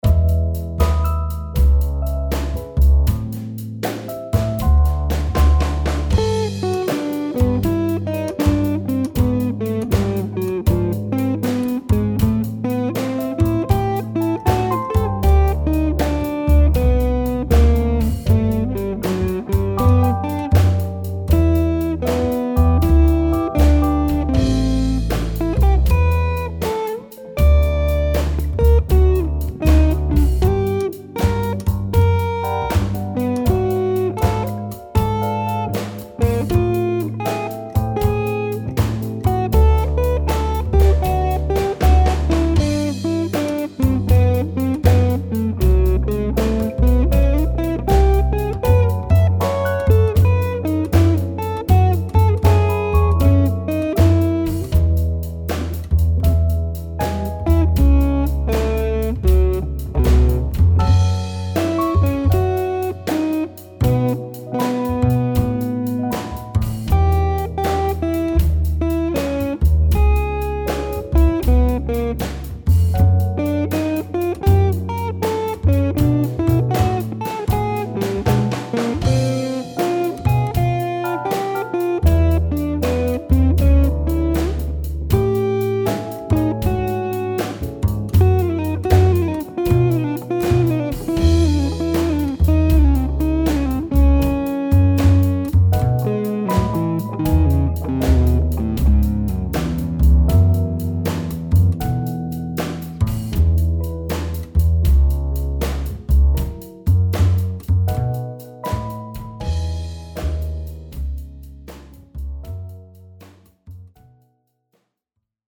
IMPROVISING Guitar Tutorial
of an improvisation style study of the Wes Montgomery tune ‘The Way You Look Tonight’.
MIX  79 BPM:    Download File